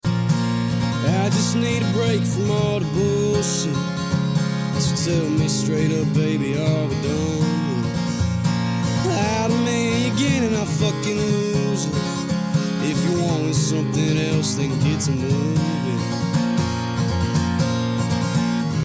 countrymusic